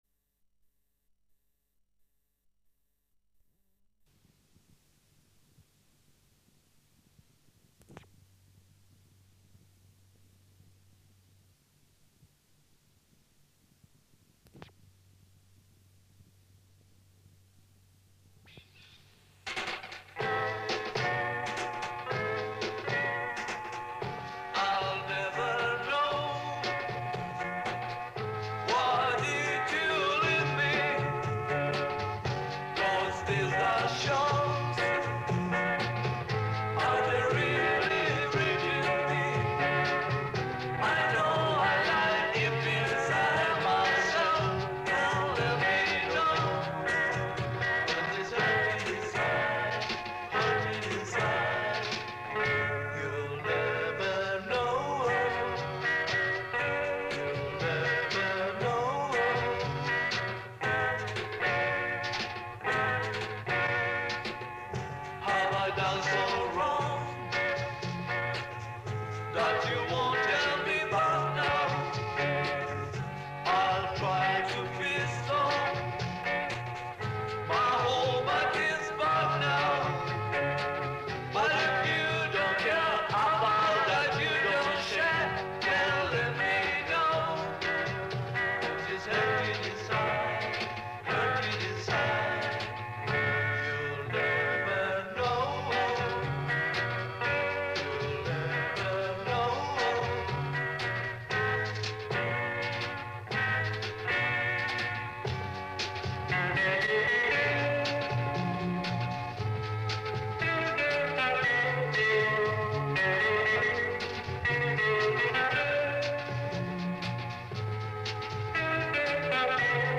Los Hammers, la banda de Rock de Punta Gorda. Edad promedio del grupo: 16 a 19 años.